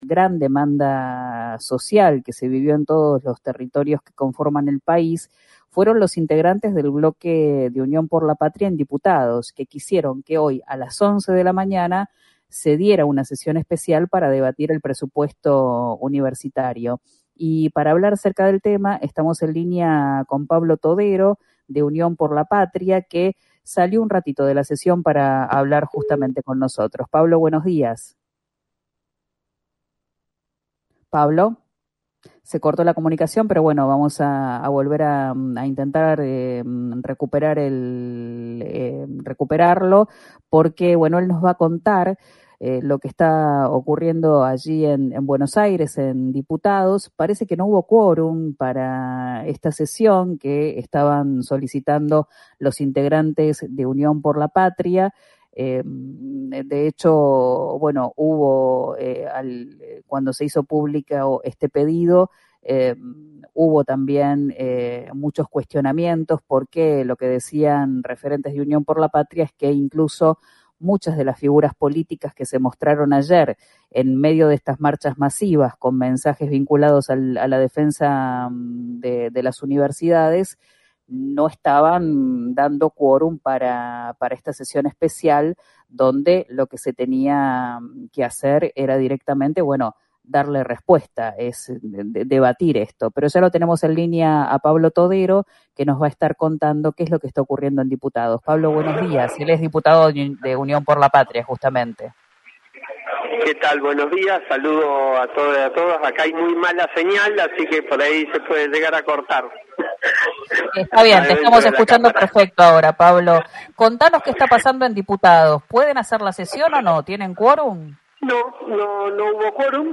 «No hubo quórum», así comenzó la confirmación del diputado por Neuquén, Pablo Todero, en diálogo con el programa «Abramos las Ventanas» de RÍO NEGRO RADIO.